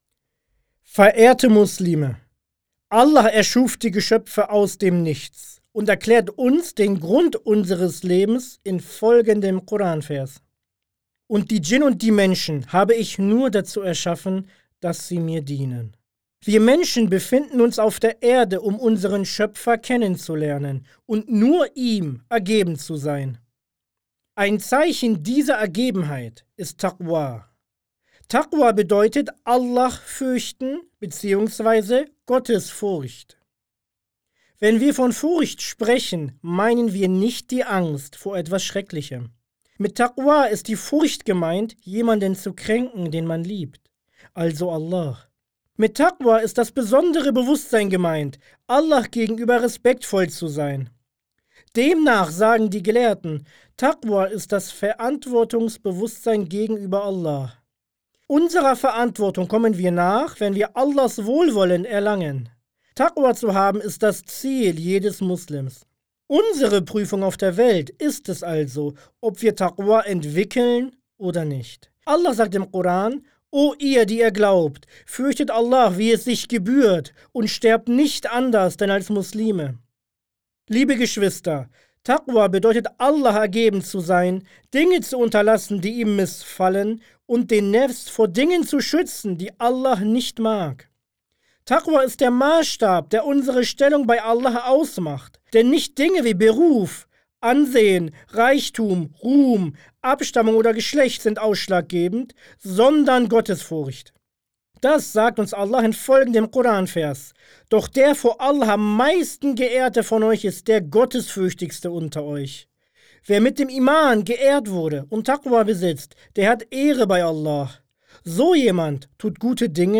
Die wöchentlichen Freitagspredigten der Islamischen Gemeinschaft Millî Görüş